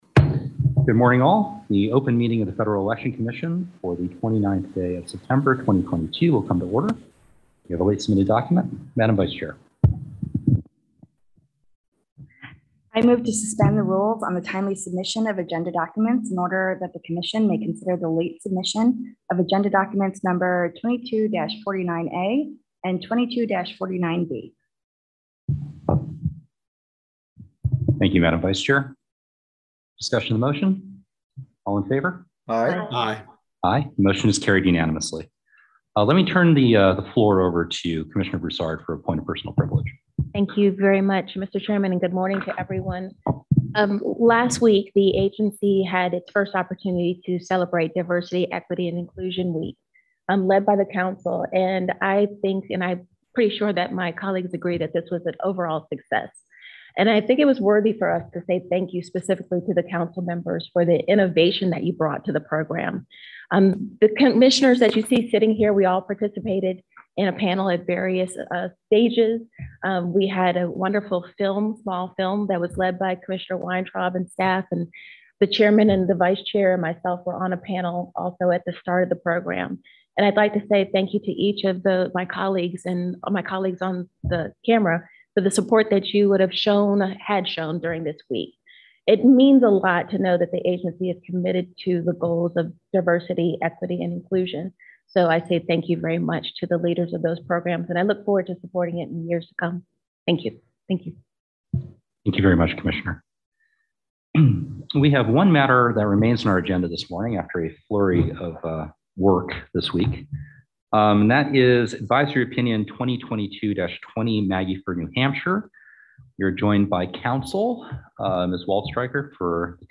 September 29, 2022 open meeting
The Commission considers new regulations, advisory opinions and other public matters at open meetings, which are typically held on Thursdays at 10:00 a.m. at FEC headquarters, 1050 First Street NE, Washington, DC.
Full meeting audio